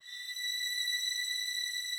strings_083.wav